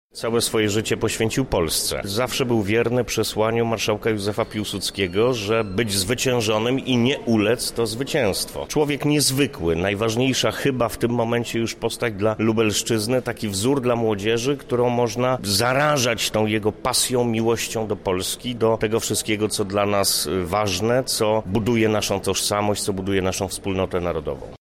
Uroczystość miała miejsce w wyjątkowym dla niego czasie, bowiem był to setny jubileusz jego urodzin.